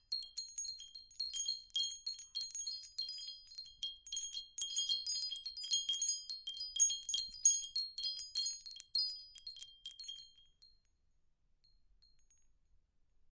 金属风铃
描述：一个小金属风铃的立体声录音。 记录在Tascam DR05上。
Tag: 钟琴 钟声